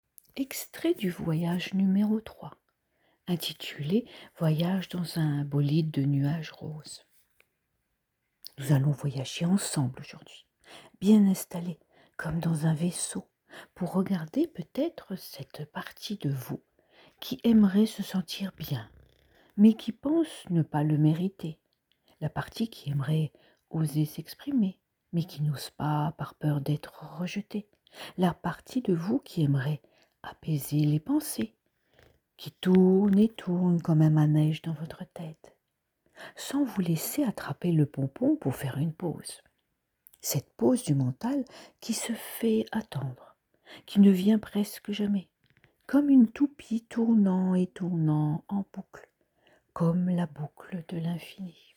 26.21 min de voyage méditatif